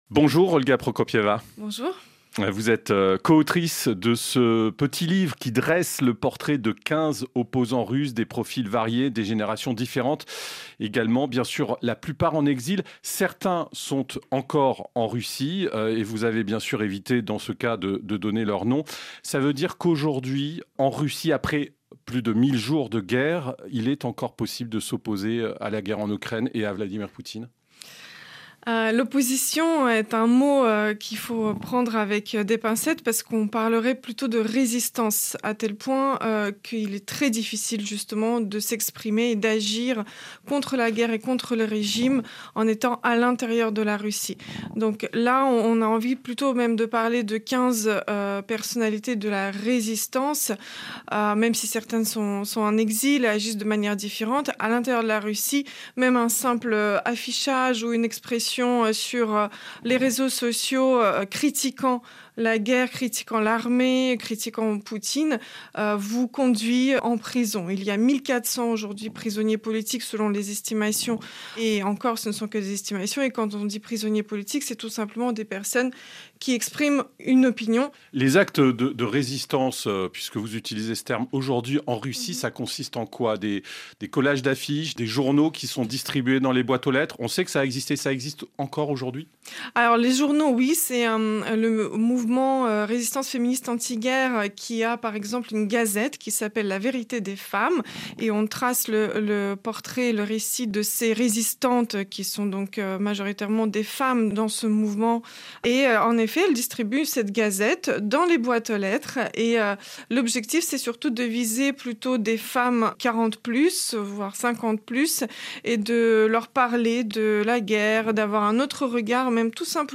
répond aux questions de RFI.